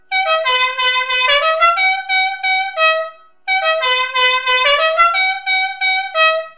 Deze luchthoorn werkt op 12V en is uitgevoerd in kunststof. Het model heeft een geluidsniveau van 116dB en is geschikt voor gebruik als waarschuwingssignaal.